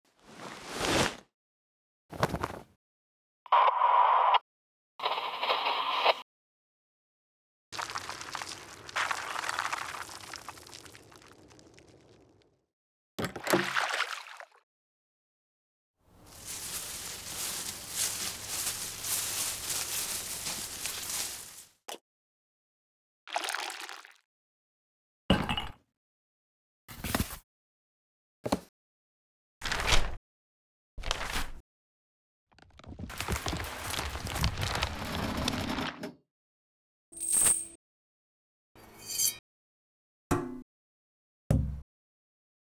Foley
In this release you will find sound interaction with everyday objects. I also included two bonus folders of unprocessed one-shot recordings of metal and percussive sounds.
All samples were recorded at 96kHz 24 bit with a Zoom H5 and Sennheiser ME 67.
Foley.mp3